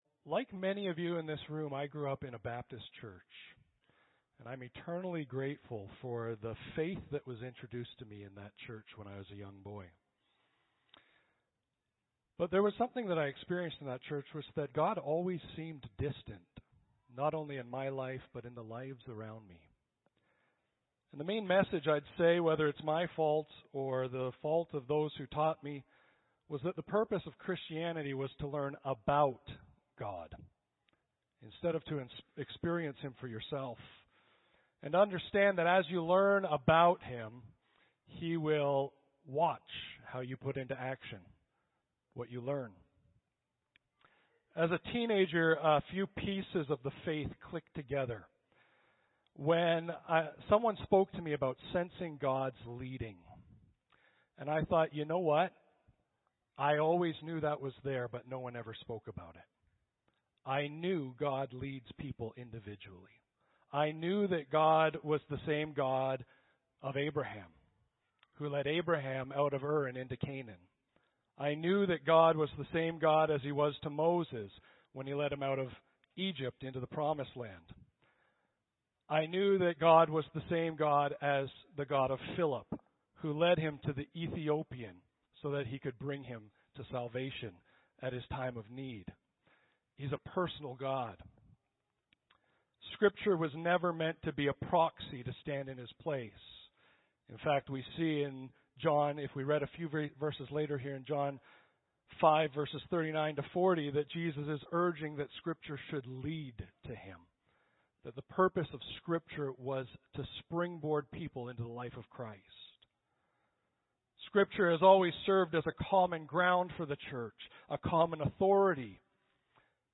Audio Sermons - Clive Baptist Church
Audio Sermon Library Vital Spirituality: Why Bother?